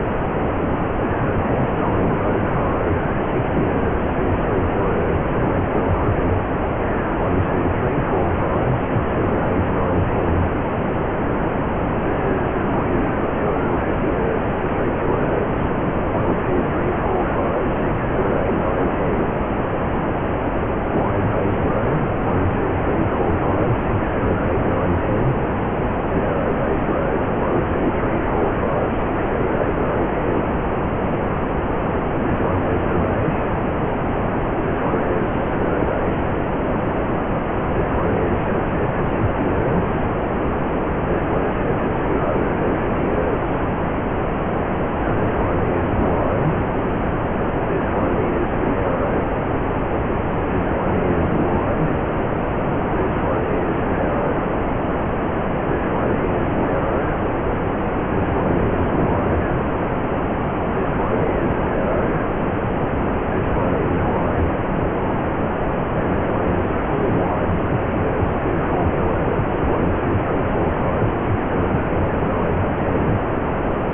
New: Mode 4 + Maximum Clipping at -6dB SNR with post EQ and lowpass filtering at 3kHz Wide:60Hz-3kHz and Narrow:250Hz-3kHz
There isn't much difference, except in tonal quality.